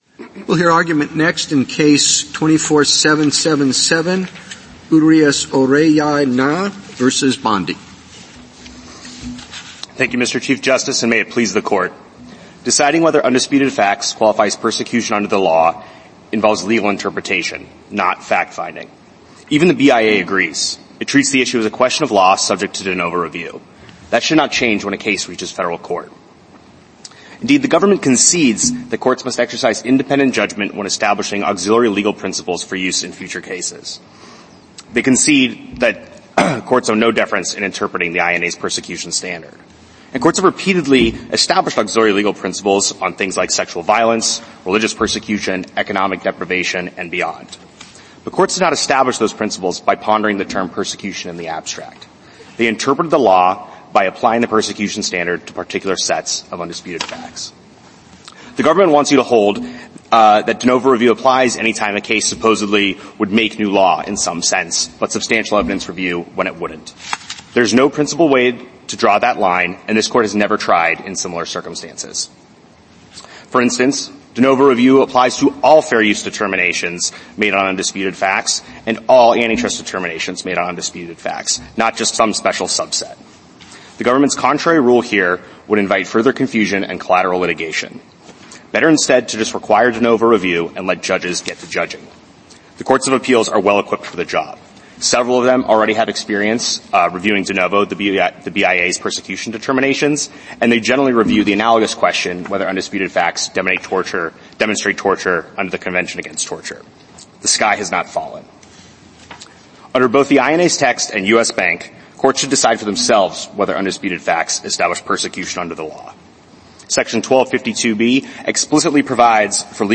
Supreme Court Oral Arguments · S2025